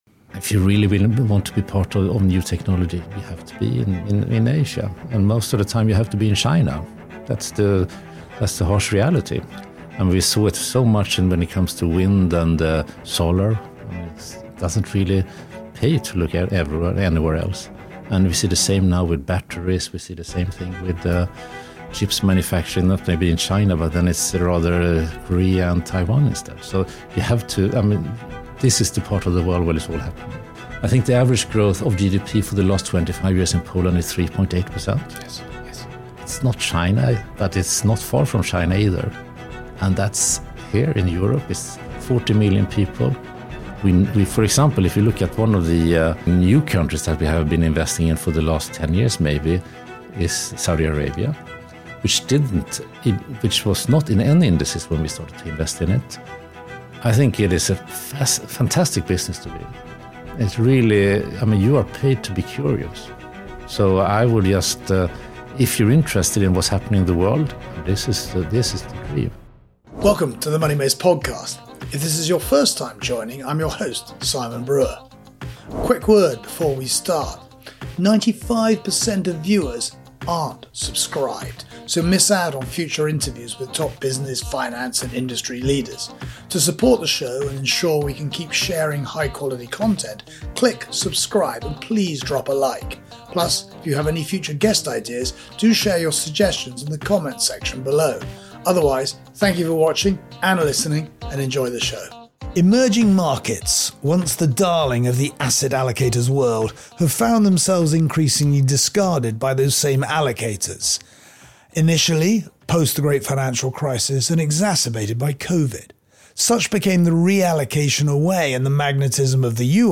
192: Emerging and Frontier Markets: Cyclical Bounce or the Beginning of a Secular Advance? A discussion